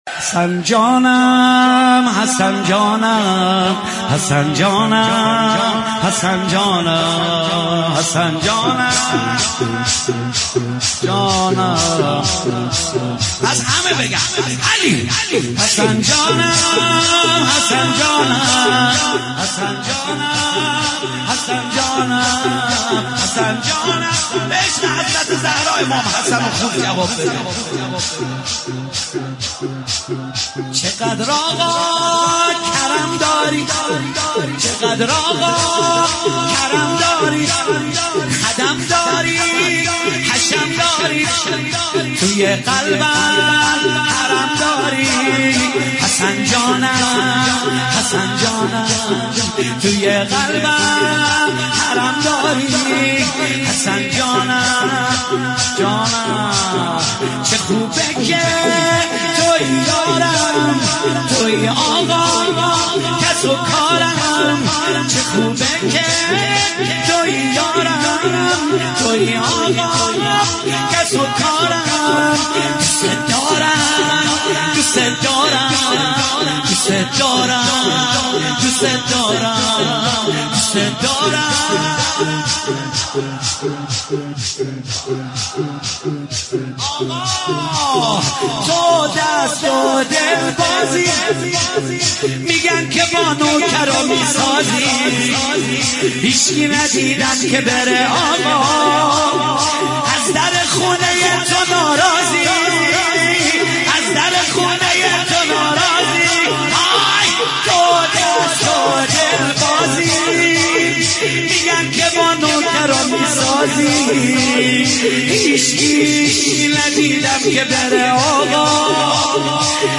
شب اول فاطمیه
(شور)